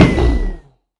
Minecraft Version Minecraft Version 25w18a Latest Release | Latest Snapshot 25w18a / assets / minecraft / sounds / mob / enderdragon / hit1.ogg Compare With Compare With Latest Release | Latest Snapshot
hit1.ogg